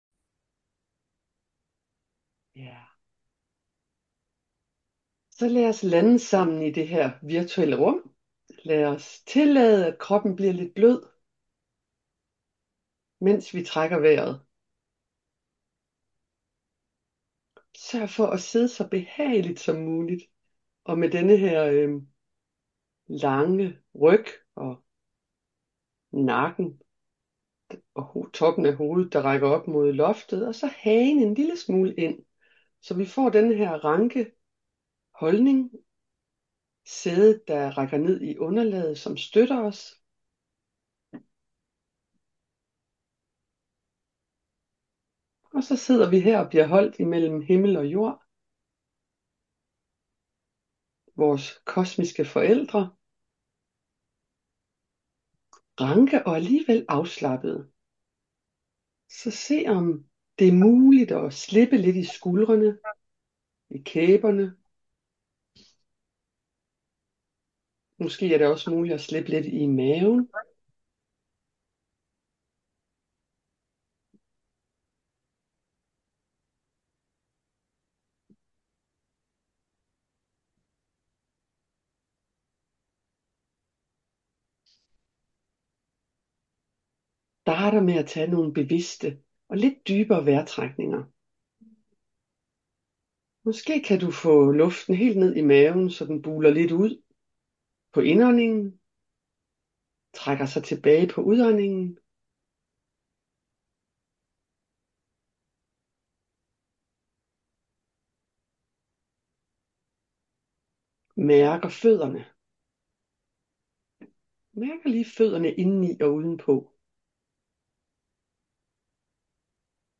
Download Guided Meditation | 7. oktober 2025 (Lydfil) ♫
Her kan du lytte til og downloade Guidede Morgen Meditationer af Mindfulness Foreningens medlemmer.